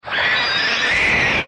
Amano_shiratori_roar.mp3